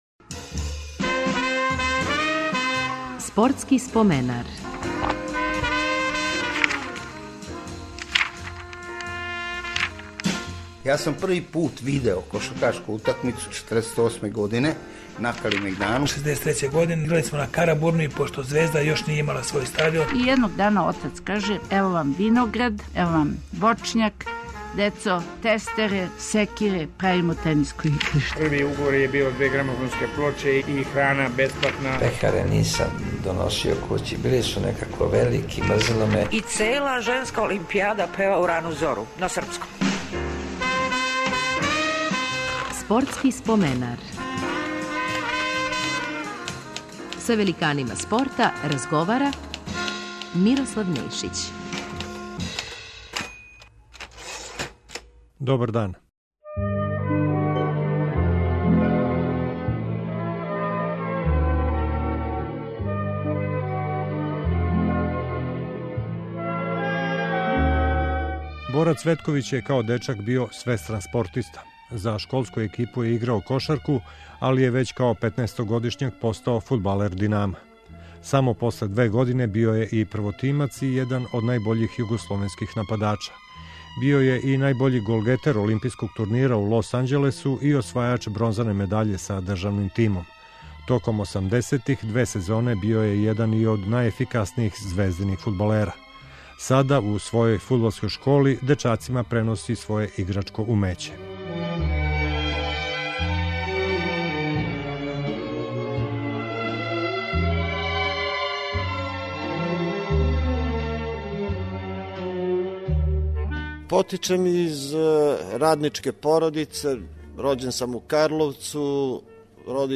Гост ће нам бити фудбалер Бора Цветковић.
У емисији ће бити коришћени и тонски записи преноса утакмица Црвене звезде са Реал Мадридом и Брижом.